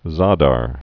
(zädär)